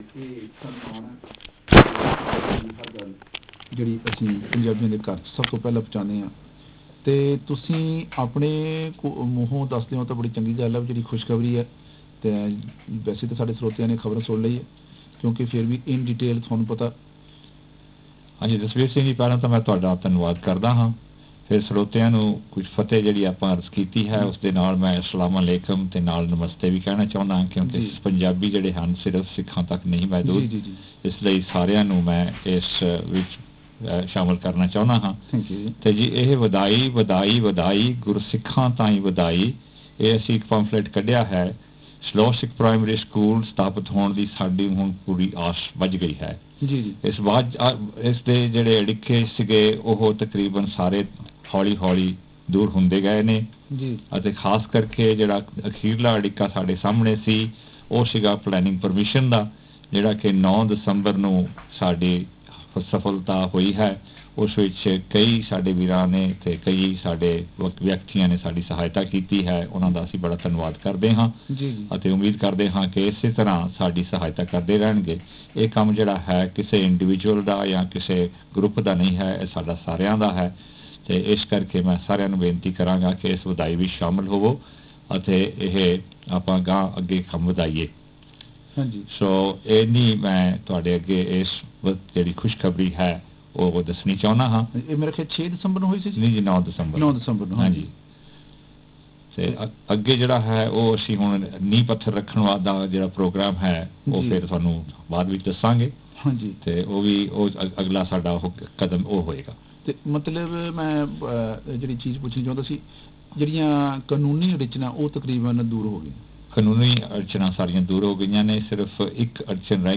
Punjab Radio discussion